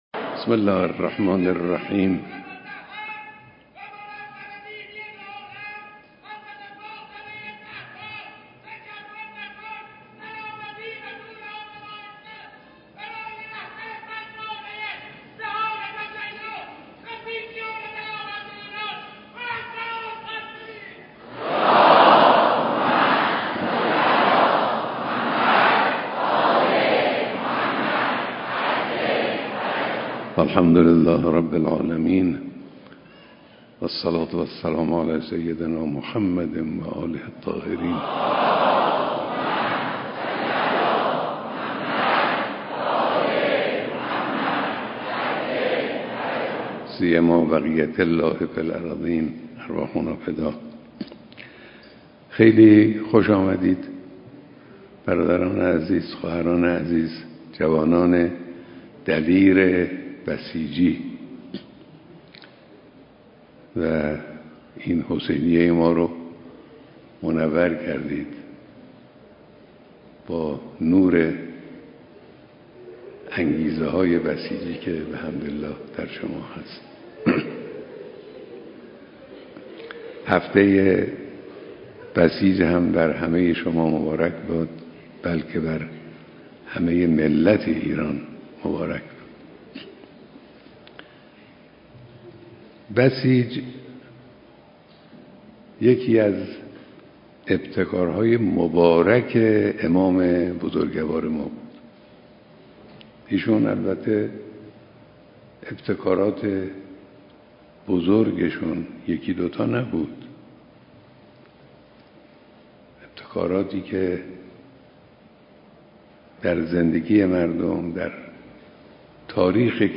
صوت کامل بیانات رهبر انقلاب در دیدار جمع کثیری از بسیجیان